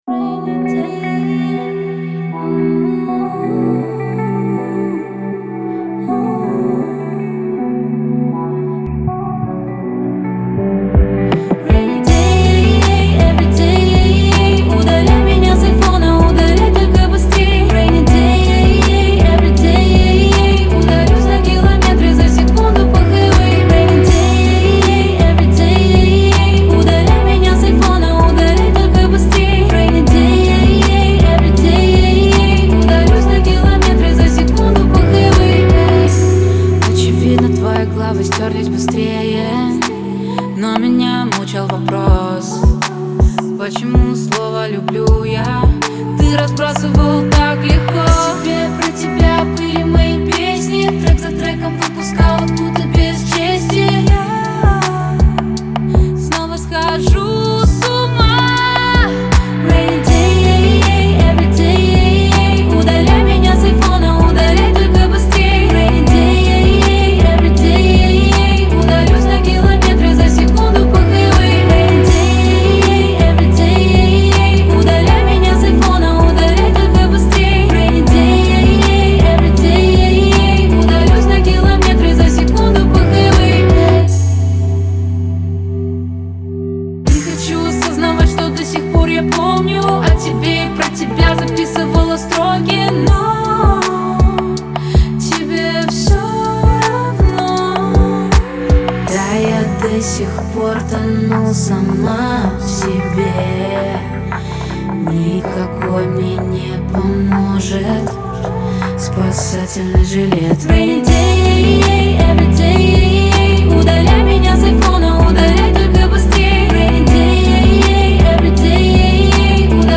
это трек в жанре инди-поп